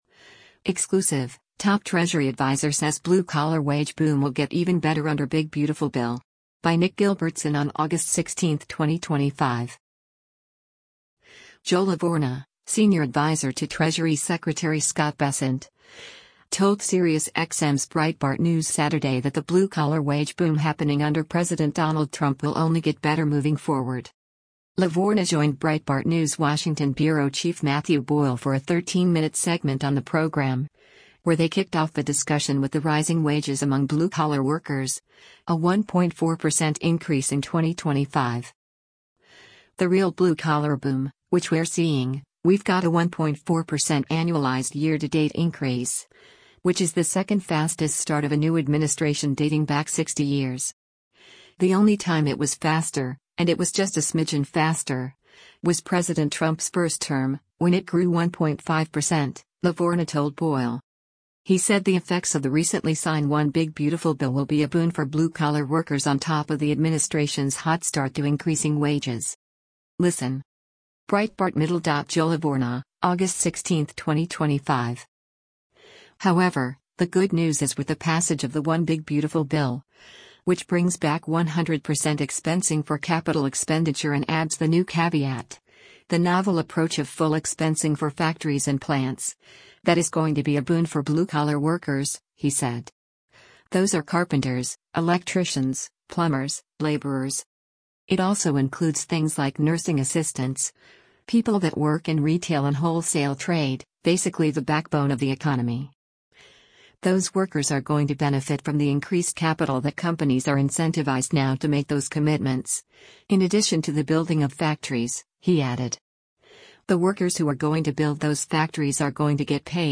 Joe Lavorgna, senior adviser to Treasury Secretary Scott Bessent, told Sirius XM’s Breitbart News Saturday that the blue-collar wage boom happening under President Donald Trump will only get better moving forward.